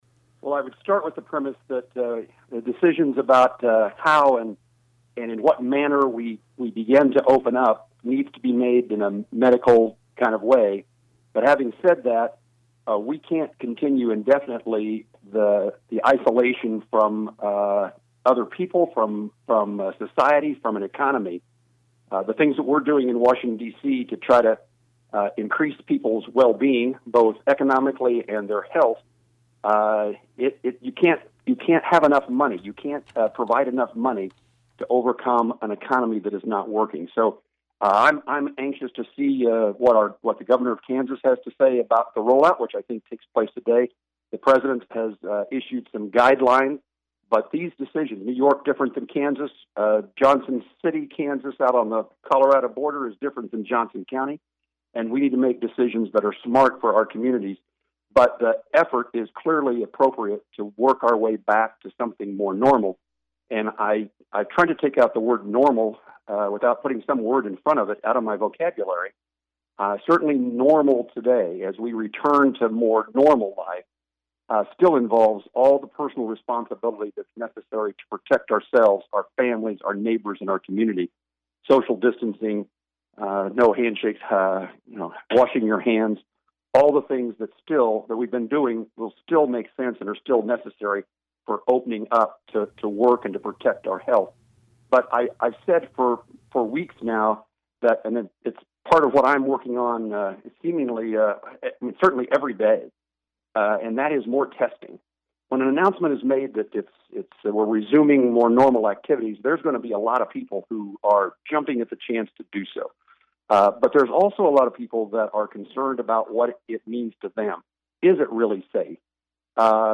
COVID-19 Q & A with U.S. Senator Jerry Moran